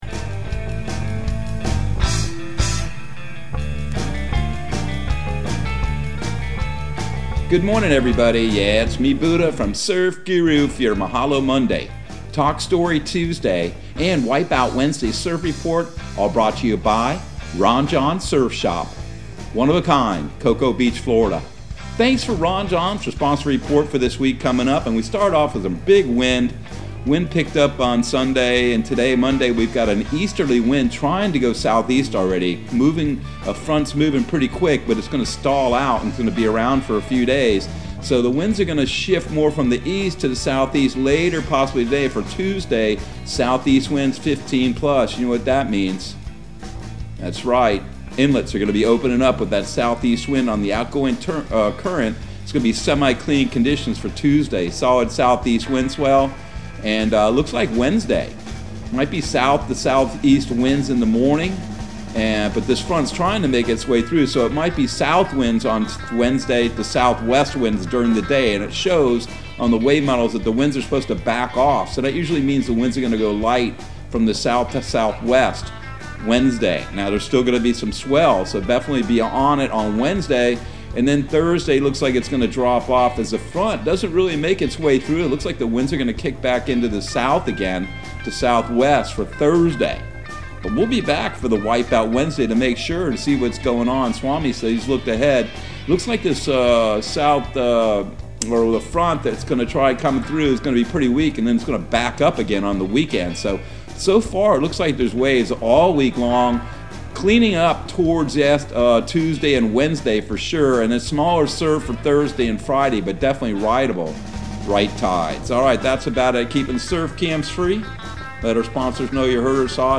Surf Guru Surf Report and Forecast 03/14/2022 Audio surf report and surf forecast on March 14 for Central Florida and the Southeast.